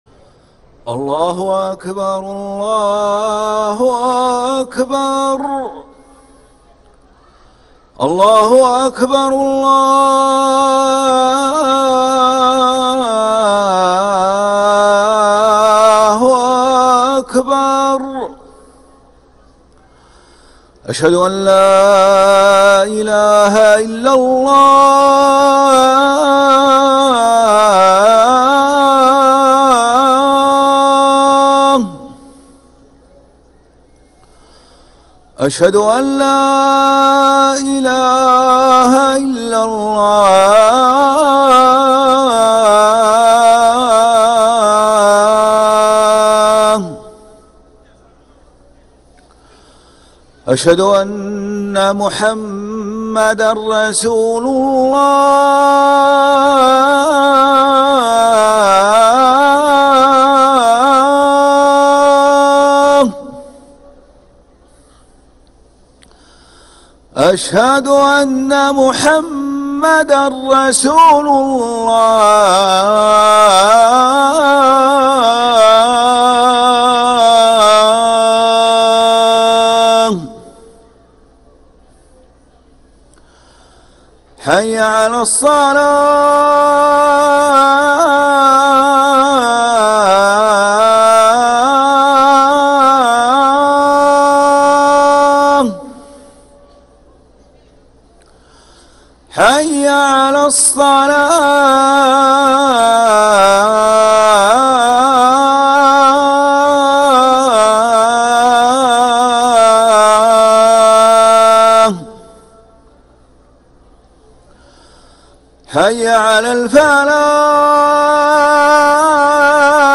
أذان العصر للمؤذن ماجد العباس الأحد 29 جمادى الأولى 1446هـ > ١٤٤٦ 🕋 > ركن الأذان 🕋 > المزيد - تلاوات الحرمين